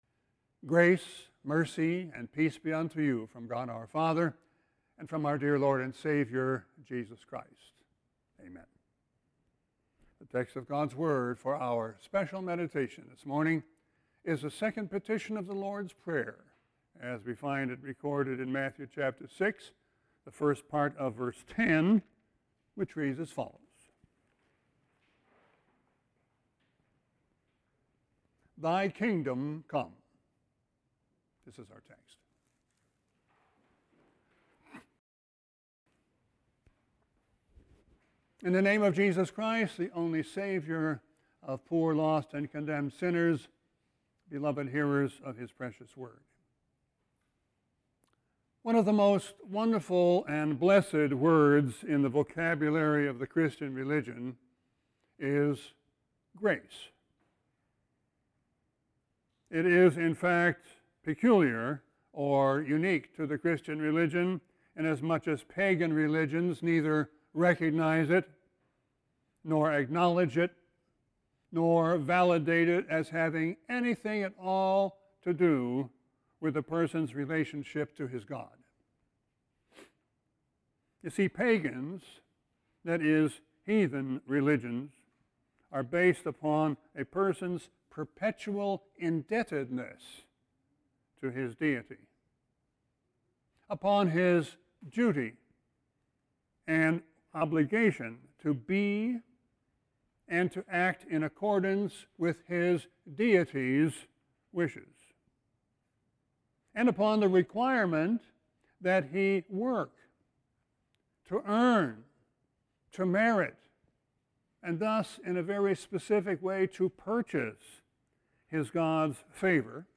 Sermon 6-27-21.mp3